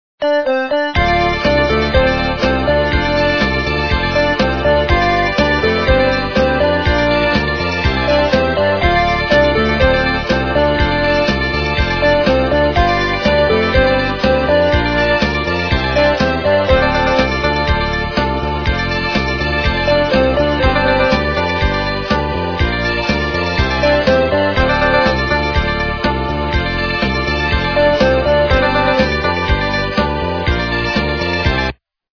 русская эстрада
качество понижено и присутствуют гудки
полифоническую мелодию